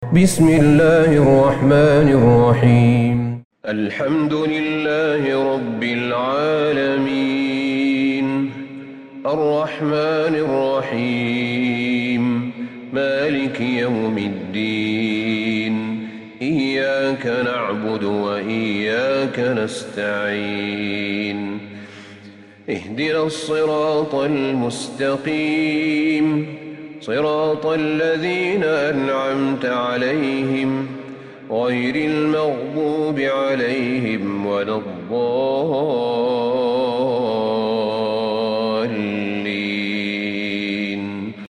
سورة الفاتحة Surat Al-Fatihah > مصحف الشيخ أحمد بن طالب بن حميد من الحرم النبوي > المصحف - تلاوات الحرمين